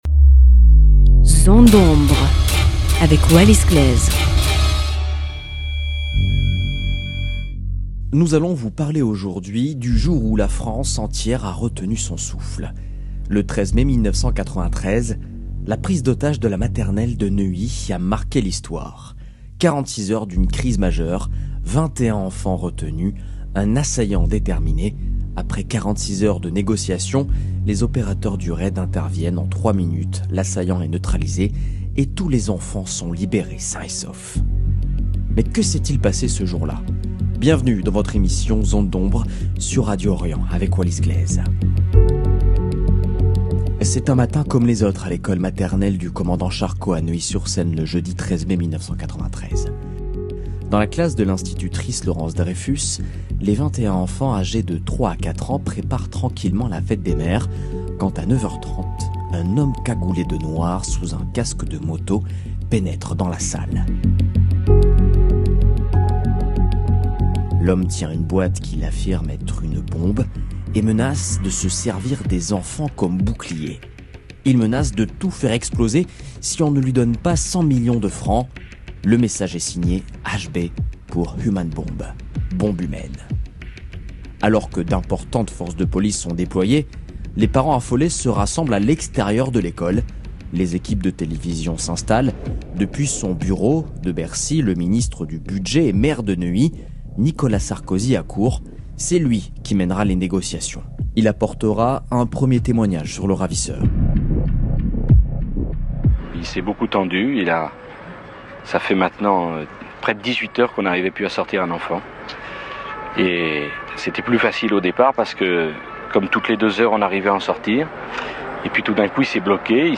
Témoignages rares, documents d’archives et récit haletant : immersion dans l’un des événements les plus marquants de l’histoire récente de France. 0:00 10 min 54 sec